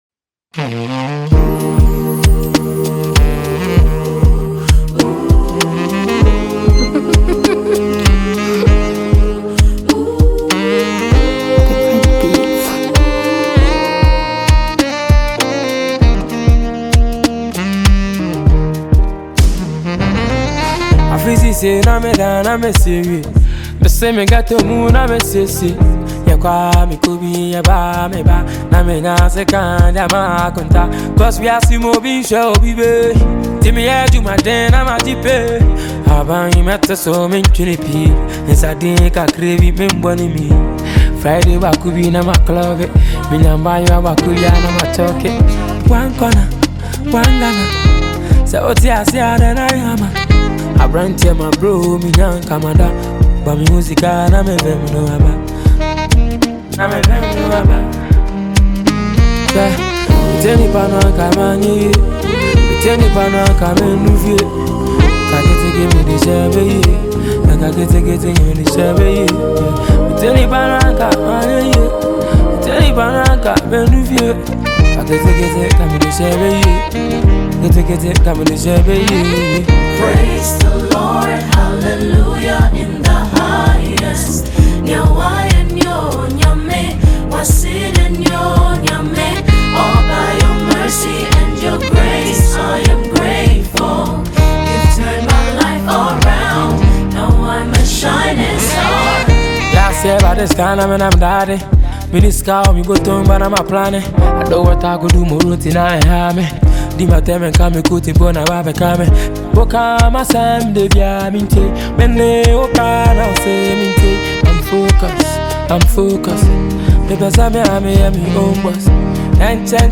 highlife song